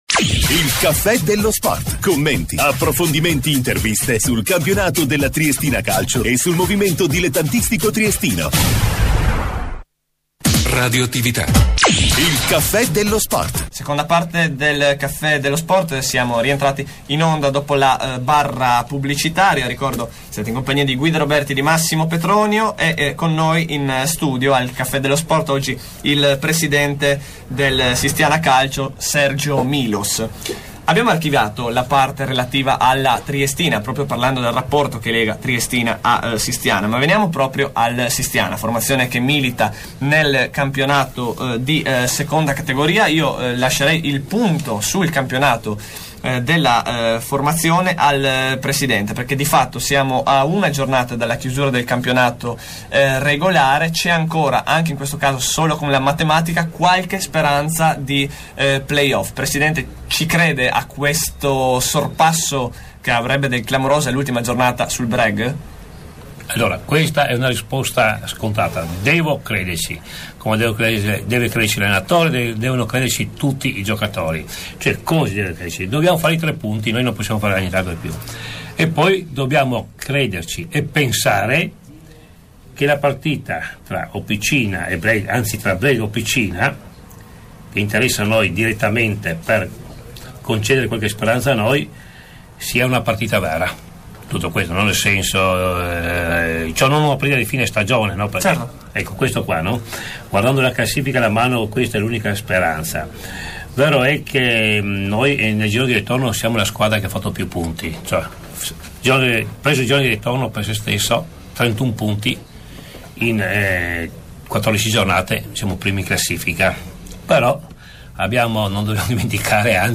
INTERVISTA A RADIOATTIVITA'